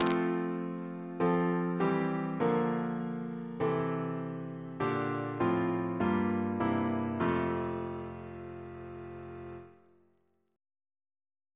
Single chant in F Composer: Sir John Goss (1800-1880), Composer to the Chapel Royal, Organist of St. Paul's Cathedral Reference psalters: ACB: 99; ACP: 117; H1940: 680; OCB: 137; PP/SNCB: 50; RSCM: 178